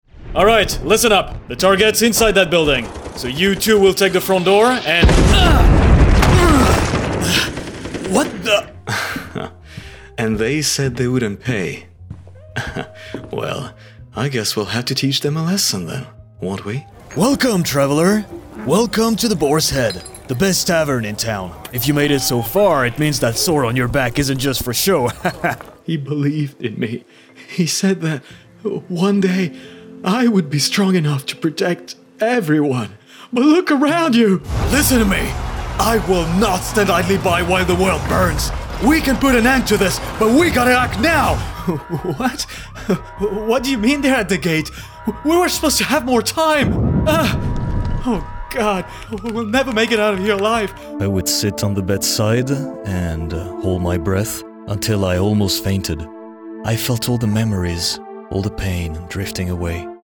Male
Assured, Authoritative, Character, Confident, Cool, Corporate, Engaging, Friendly, Gravitas, Natural, Reassuring, Smooth, Warm, Versatile, Soft
Microphone: Audio Technica AT2020 USB+
Audio equipment: Sound-treated booth, Beyerdynamic DT250 headphones, SourceConnect Now, ipDTL, Skype